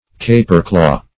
Search Result for " caperclaw" : The Collaborative International Dictionary of English v.0.48: Caperclaw \Ca"per*claw`\, v. t. To treat with cruel playfulness, as a cat treats a mouse; to abuse.